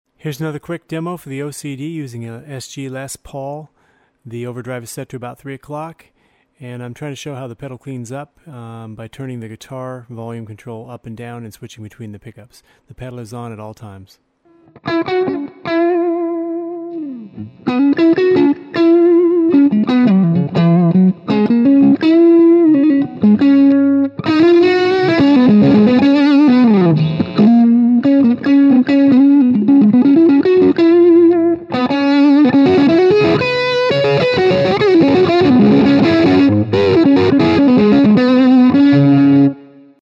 Category Distortion Pedal